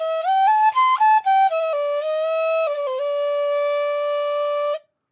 木管乐器和锣合成中国风
描述：木管乐器和锣合成的中国风音效。
标签： FX 合成器 木管乐器 中国风
声道立体声